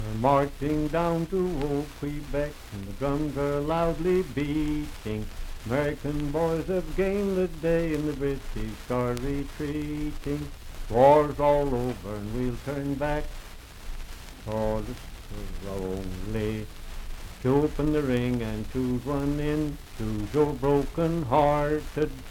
Unaccompanied vocal music
Dance, Game, and Party Songs
Voice (sung)
Franklin (Pendleton County, W. Va.), Pendleton County (W. Va.)